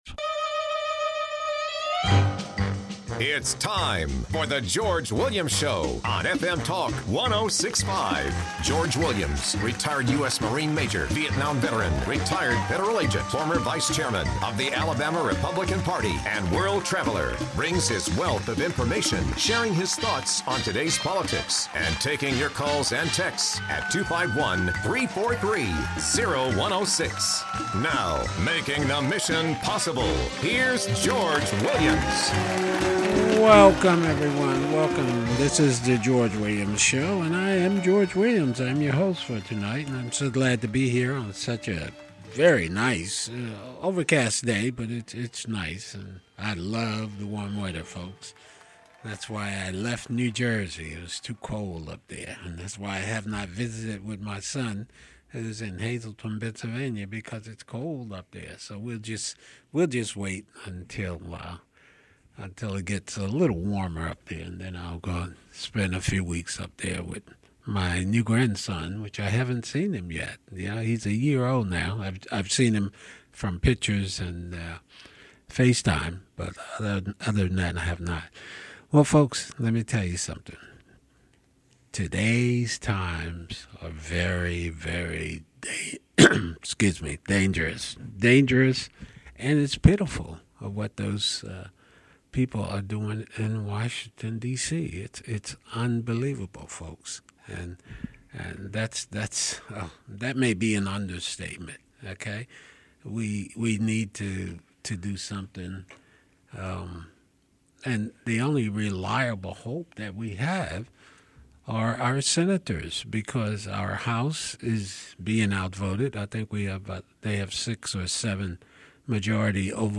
CRT, civil rights reform, the Russian oil pipeline, and Senate Bill 1 are covered in the interviews